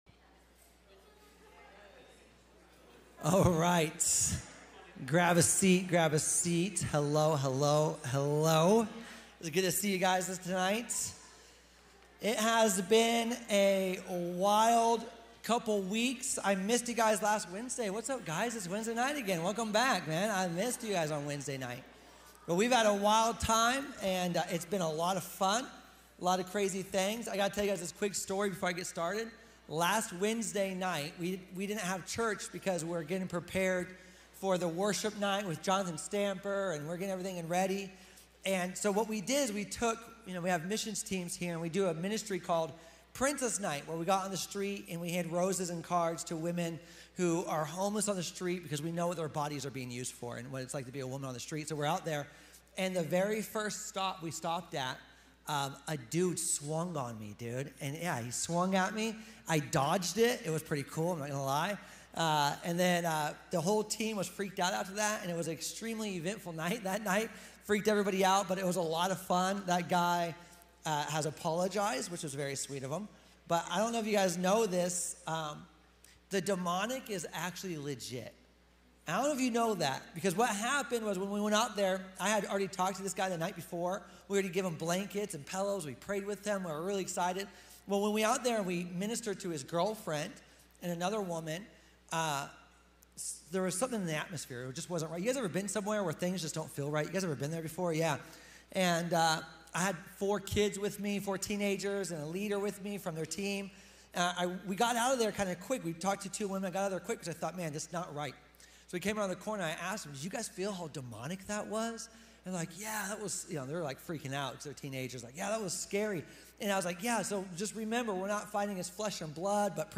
Today you can join in on services, outreach, fellowship and participate in discipleship every Sunday at 10am.